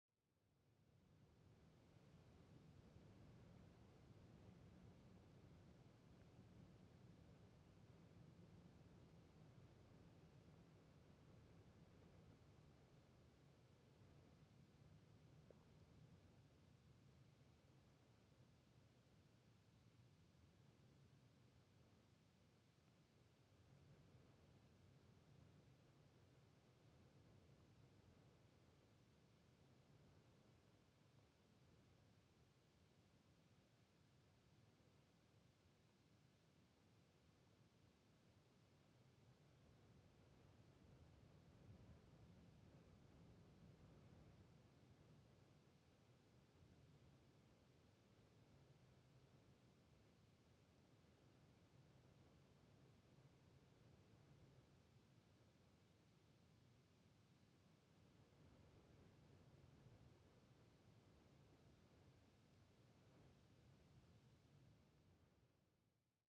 Unbearable heat throughout the summer months of Badwater Basin deters most human and wildlife activity in the region. The briny barren landscape creates a soundproofing effect as wind scrapes across these salt flats. There was clumpy, sparse foliage in this area that acted as a source of sound during gusty wind conditions. Other sound sources in this area include small birds, insects near the foliage, some vehicles (automobiles, motorcycles and ATV’s) on the West Side Road, visitor noise (hikers to Tule Spring), commercial jet aircraft, fixed-wing aircraft, and one helicopter.